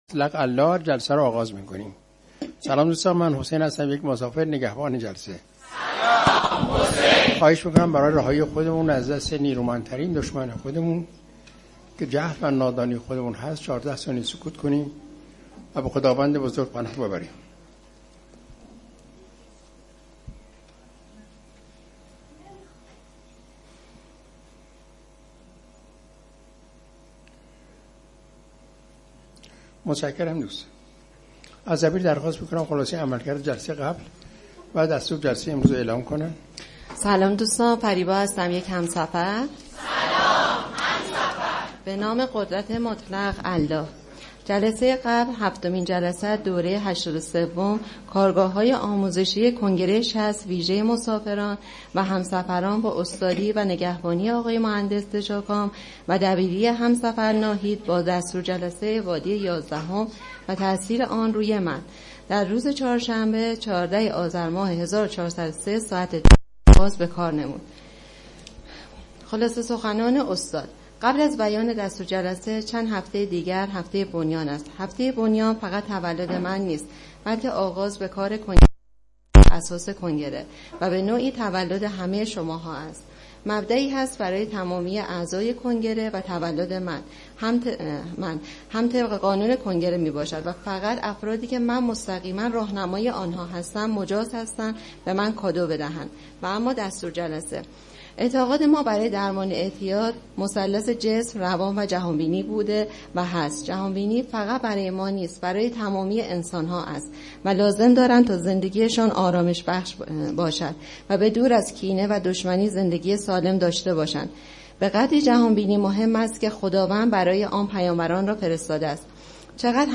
کارگاه آموزشی جهان‌بینی؛OT